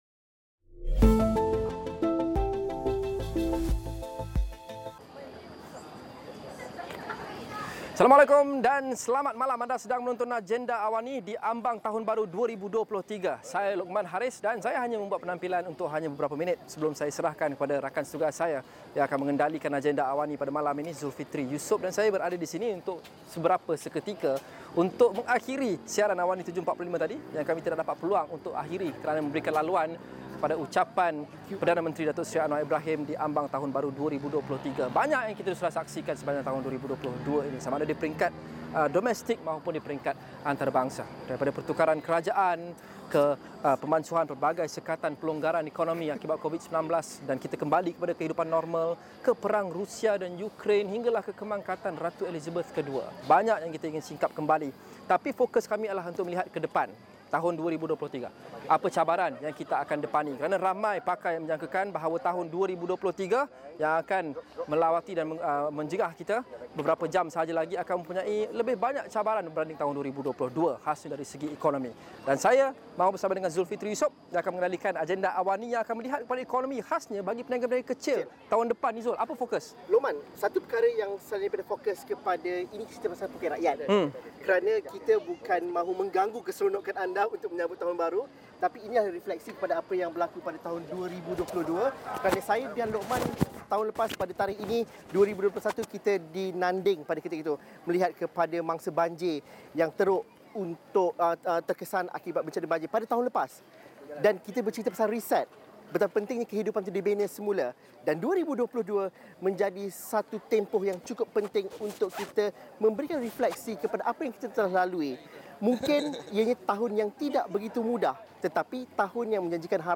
Cabaran ekonomi 2023 menuntut idea dan formula baharu untuk pastikan ekonomi negara dan poket rakyat terjamin. Siaran Luar dari Tapak Urban Street Dining @ Cyberjaya bermula 7.45 malam.